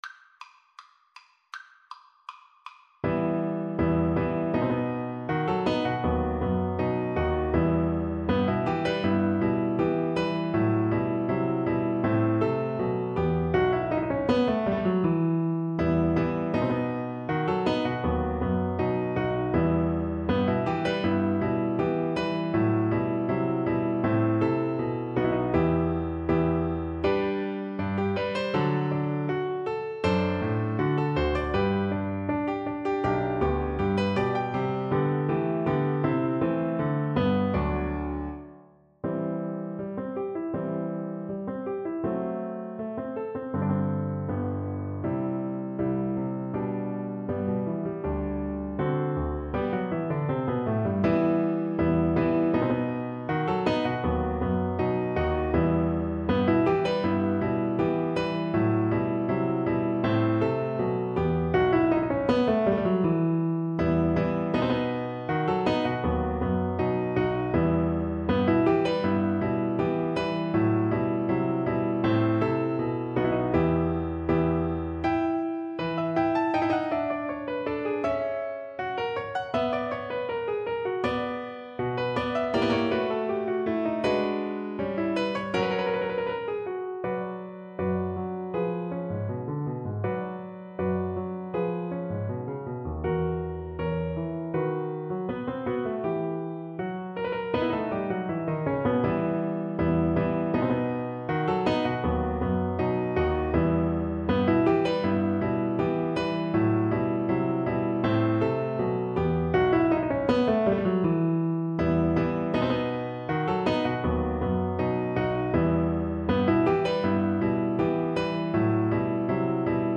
Classical Rameau, Jean-Philippe Danse des Sauvages from Les Indes Galantes Flute version
Flute
E minor (Sounding Pitch) (View more E minor Music for Flute )
Rondeau =80
2/2 (View more 2/2 Music)
Classical (View more Classical Flute Music)
Dramatic & Epic music for Flute
Baroque Music for Flute